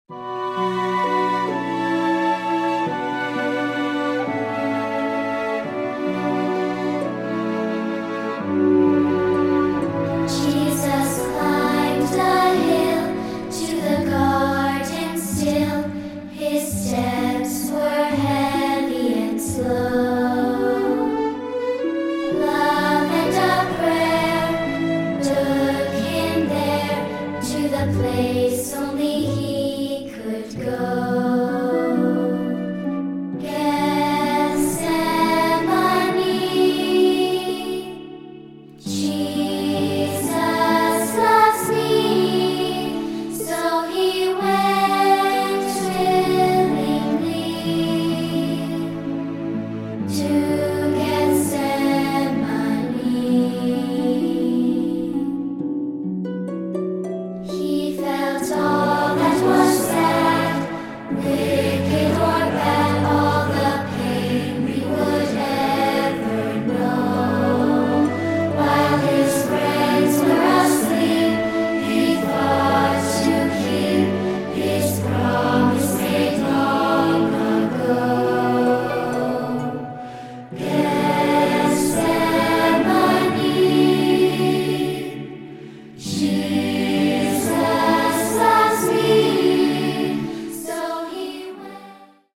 with adult choir and orchestra